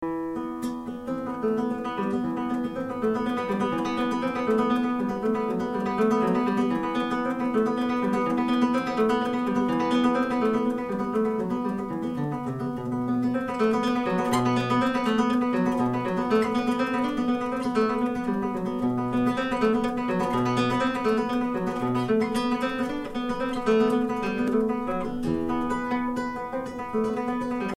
2. Über simples Ansteckmikro:
Aufnahmen mit simplem Ansteckmikro (mono) etwa einen Meter von Schallquelle bzw. im Proberaum an Wand befestigt.
Beispiel 1 laute Bandprobe (Empfindlichkeit auf "Line In")